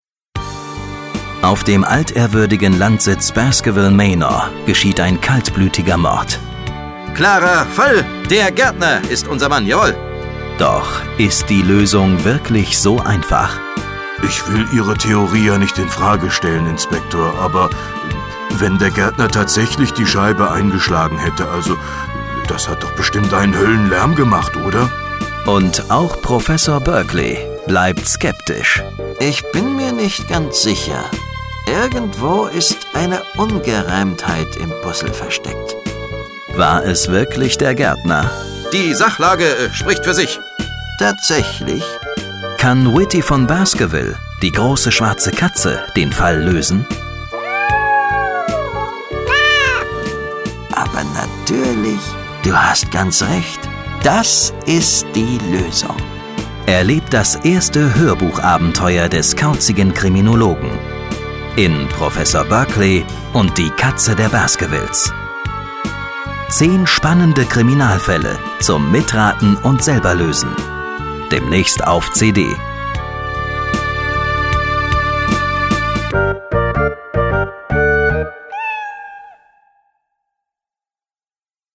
berkley_trailer_bd_1_demnaechst.mp3